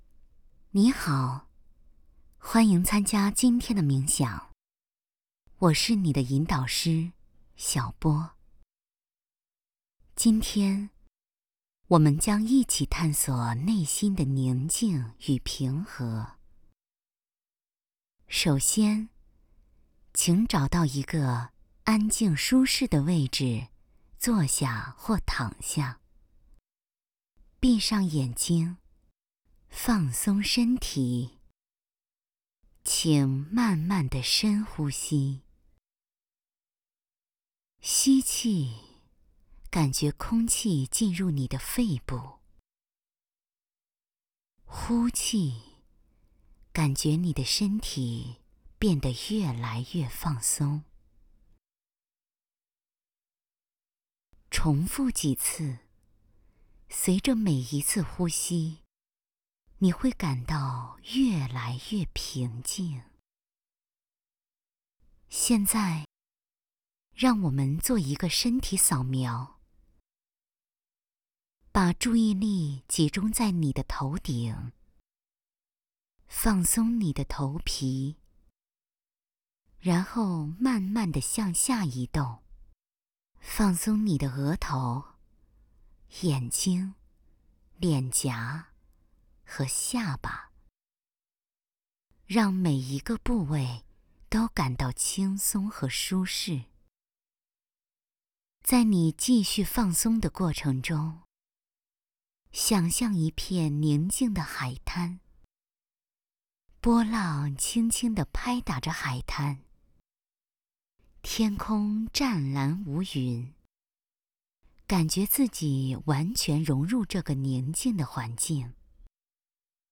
Chinese_Female_004VoiceArtist_15Hours_High_Quality_Voice_Dataset
Calm Style Sample.wav